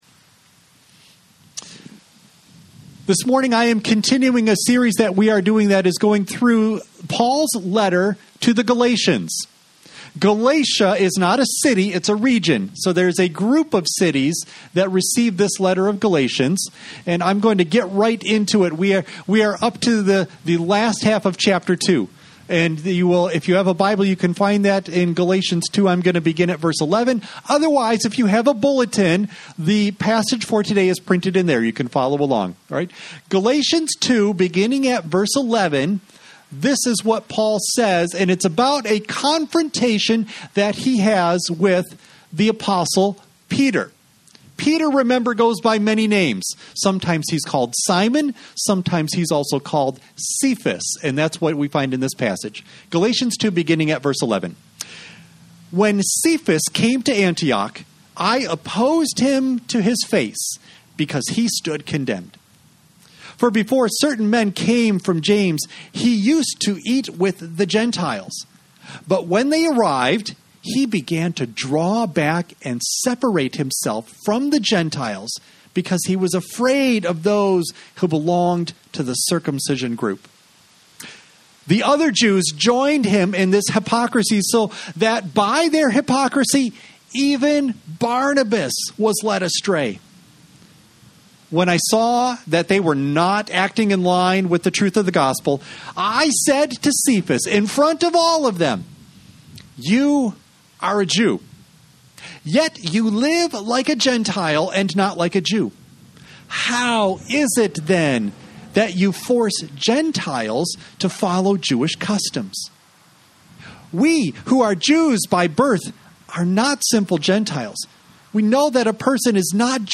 You may download and print the BULLETIN for this service as well as sermon NOTES for children from the Download Files section at the bottom of this page Worship Service September 27 Audio only of message